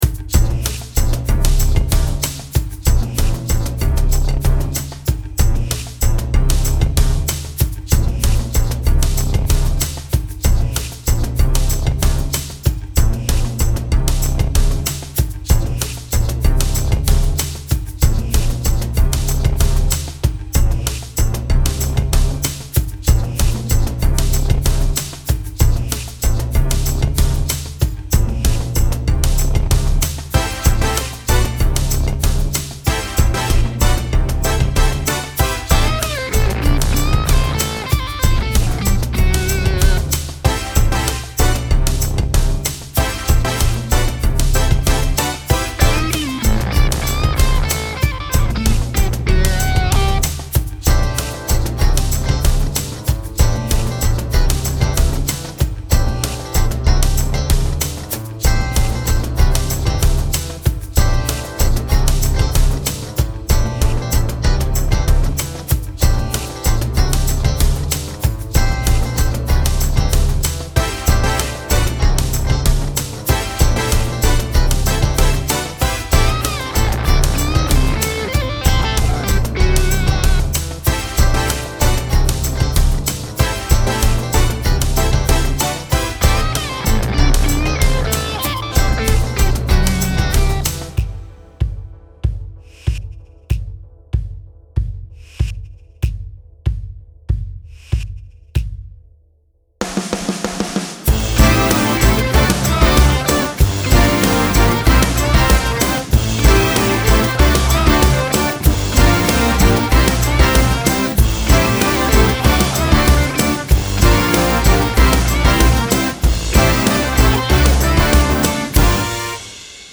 Playback | Choreographie-Video